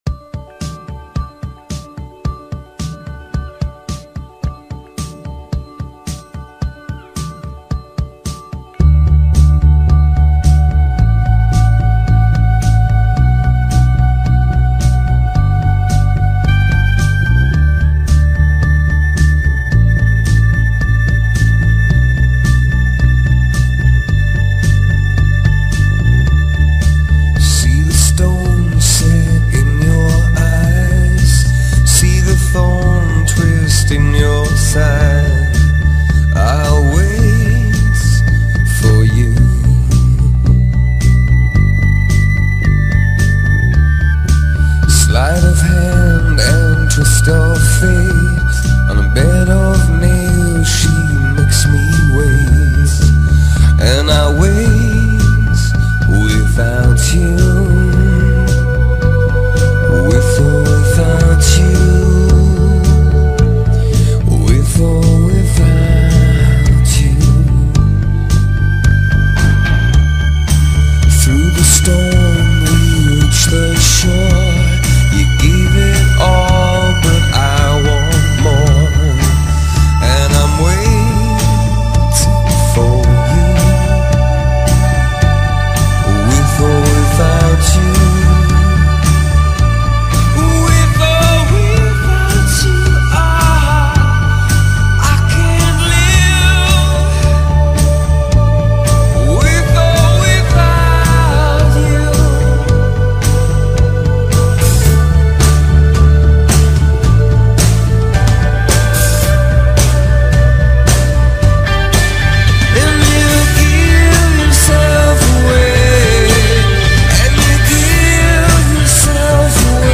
Som encorpado
Pop Rock